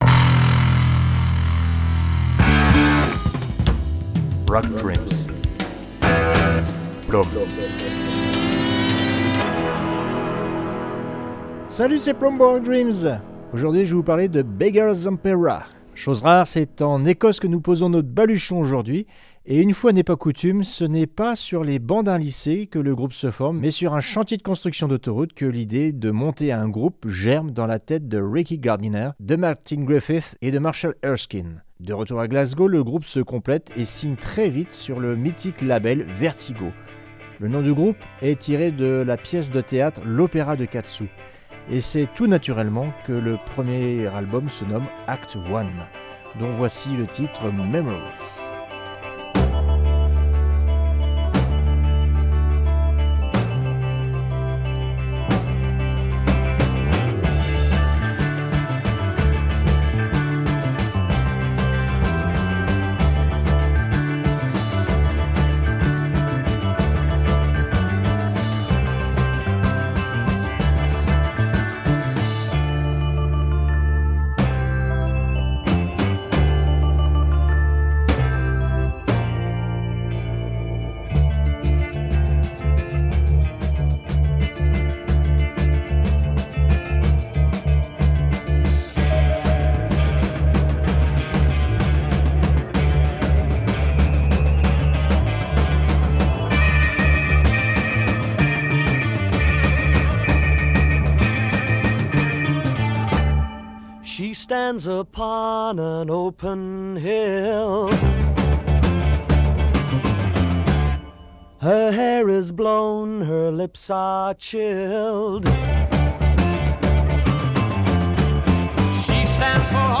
Heavy Prog